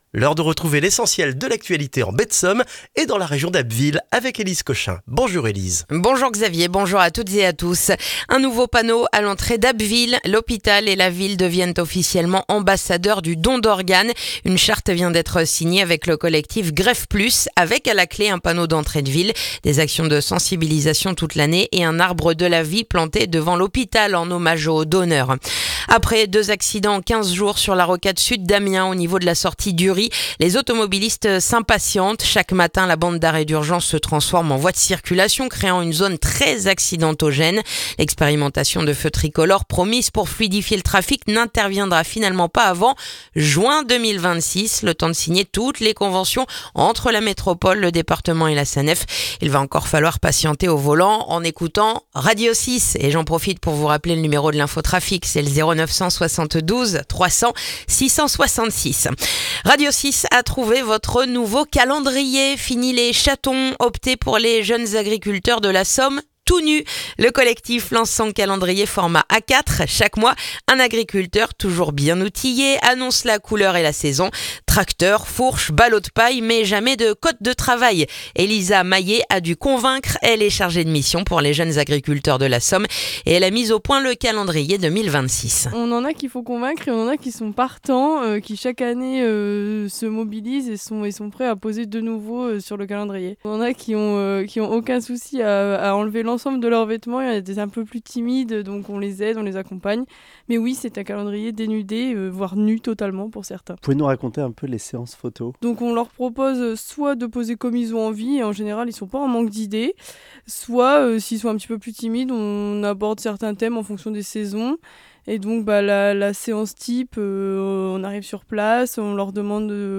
Le journal du jeudi 4 décembre en Baie de Somme et dans la région d'Abbeville